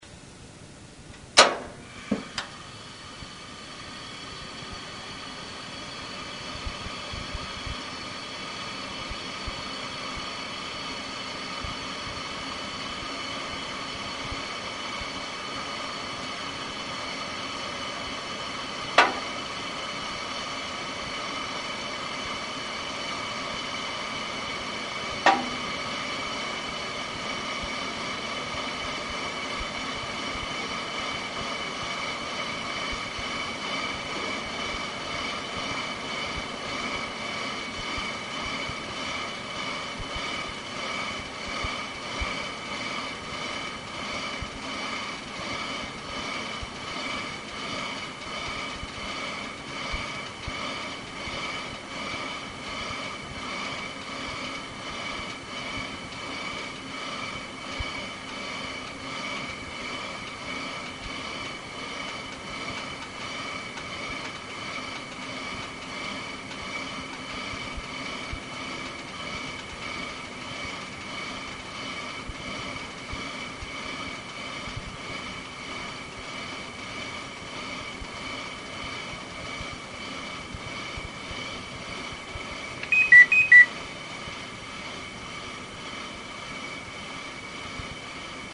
FW-5590L再点火の時の音　500kB　2分間
ガンという音は、気化器のソレノイドがプランジャーを吸引しノズルが開 く時の音。
シューシューがバーナーの音、　脈動（気化ガス噴出量が周期的に変動する） がわかるかな。ダイニチのファンヒーターは脈動が気になる。
先に録音したやつは、市販のコンデンサーマイク素子を箱に入れた自作のマ イクです。